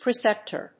音節pre・cep・tor 発音記号・読み方
/prɪséptɚ(米国英語), prɪséptə(英国英語)/